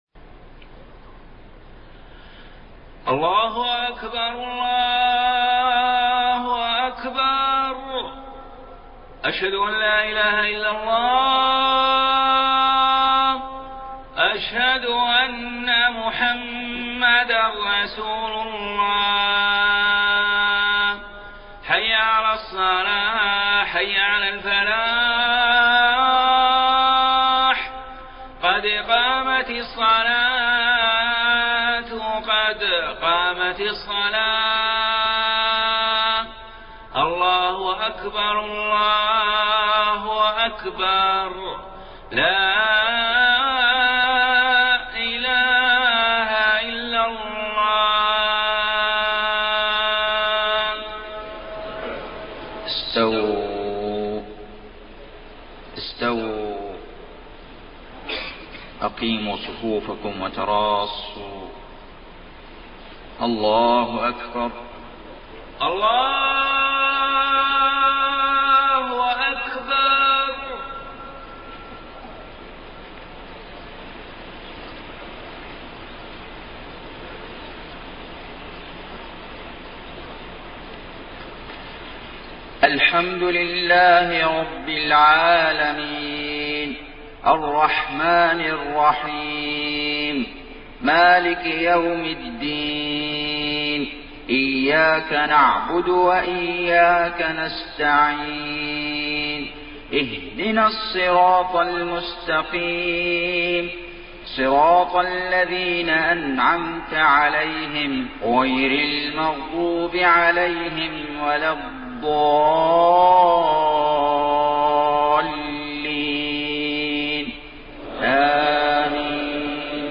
صلاة الفجر 16 رجب 1433هـ سورة المدثر > 1433 🕋 > الفروض - تلاوات الحرمين